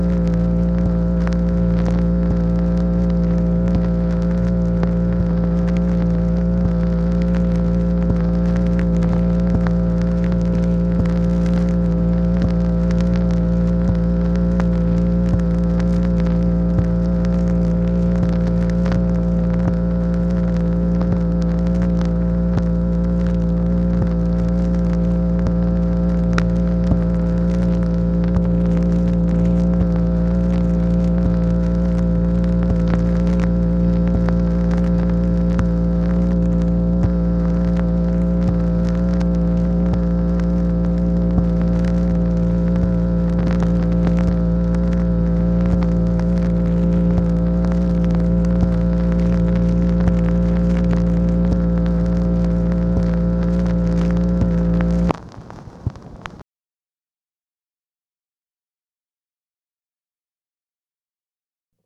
MACHINE NOISE, September 30, 1968
Secret White House Tapes | Lyndon B. Johnson Presidency